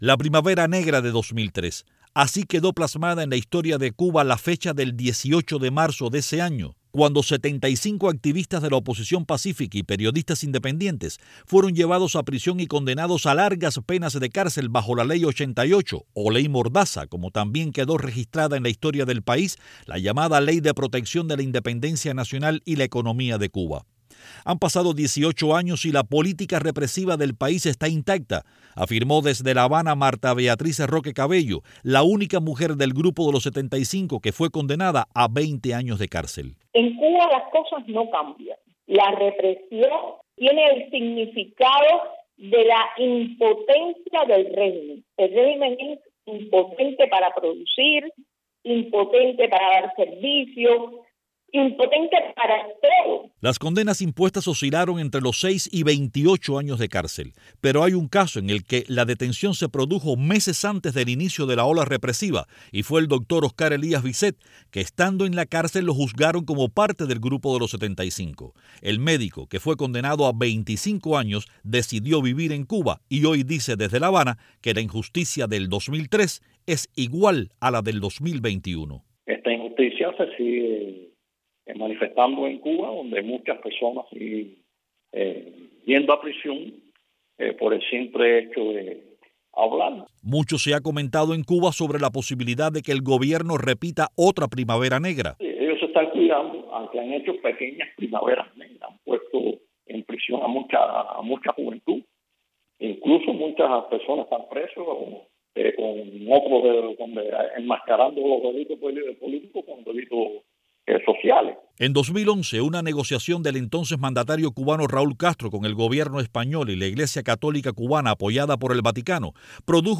Ex prisioneros cubanos hablan de la Primavera Negra de 2003